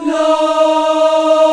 AFROLA D#4-L.wav